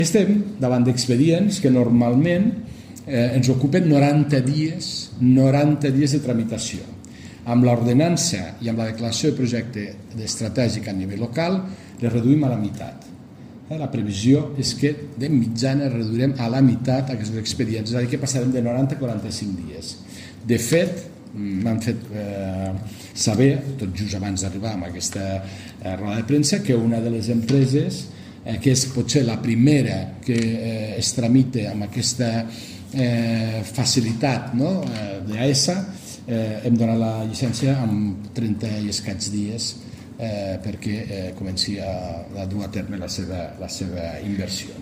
Talls de veu
Tall de veu de l'alcalde de Lleida, Fèlix Larrosa, sobre la reunió amb ajuntaments de Lleida i de l’Aragó per tractar sobre la línia de rodalies Lleida-Montsó